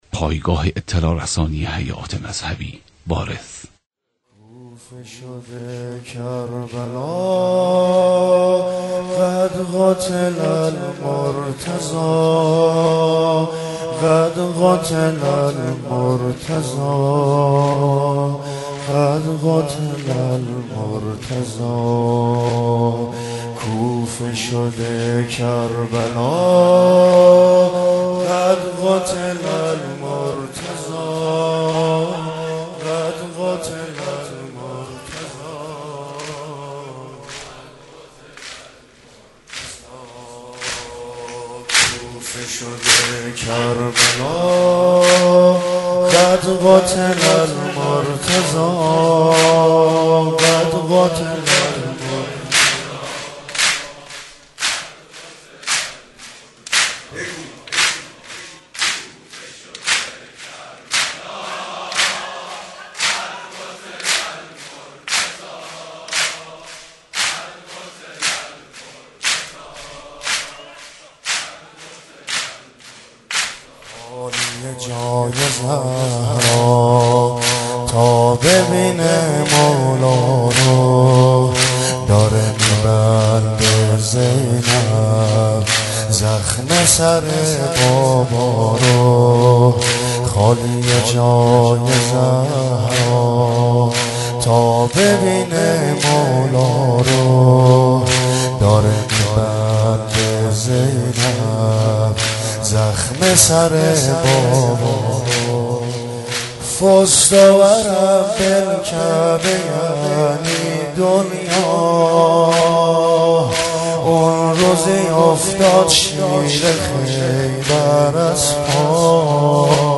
مداحی حاج عبدالرضا هلالی به مناسبت شهادت امیرالمومنین (ع)